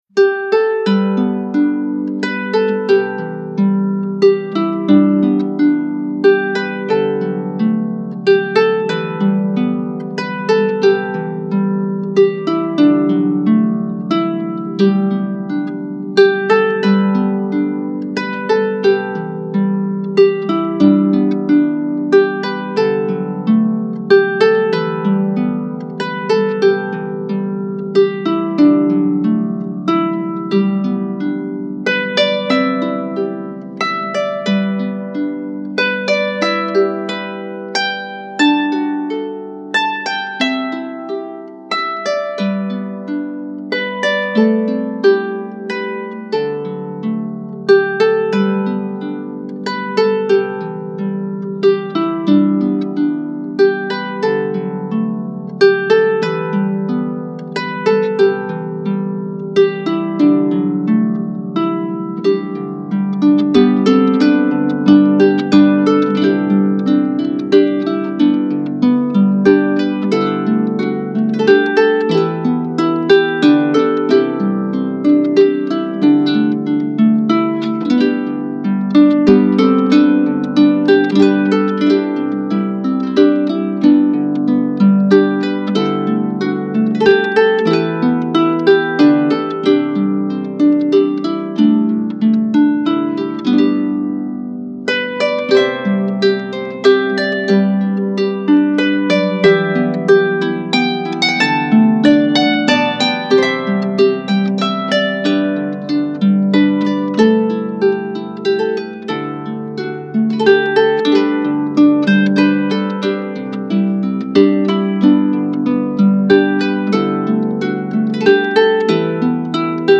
Scottish Music